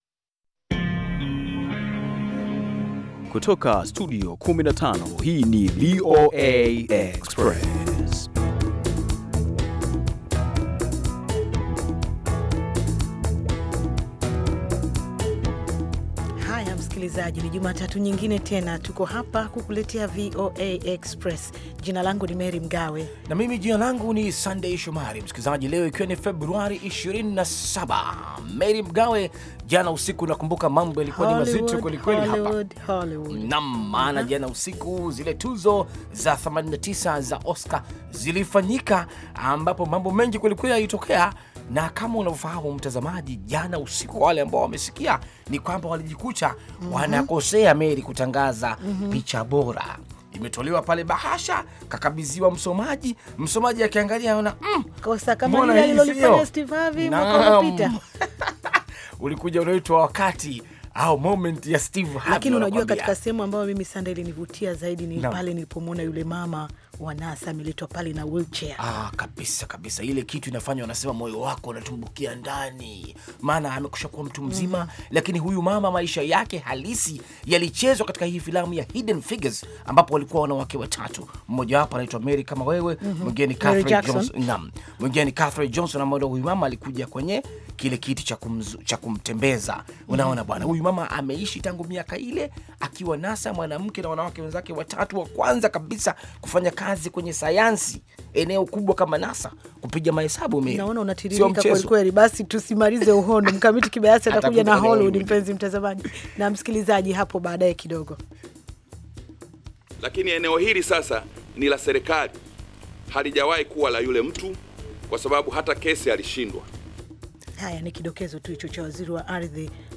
VOA Express ni matangazo mapya yenye mwendo wa kasi yakiangalia habari mpya za mchana na maelezo ya maswala yanayohusu vijana na wanawake. Matangazo haya yanafuatilia habari zilizojitokeza nyakati za mchana na ripoti za kina za habari ambazo hazisikiki sana katika matangazo mengineyo.